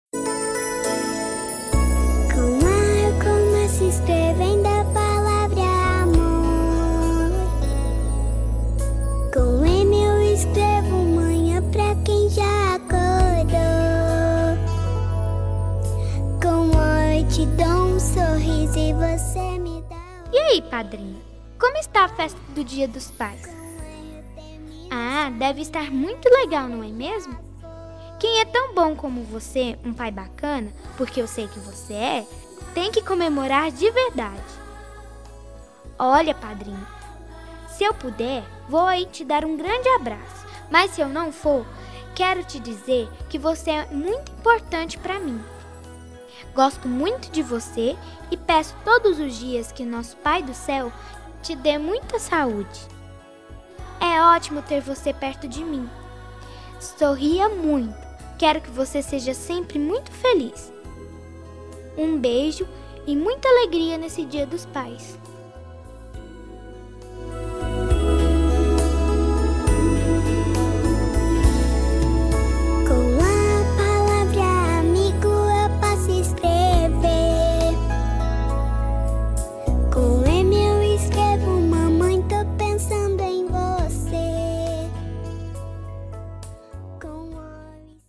Voz de Criança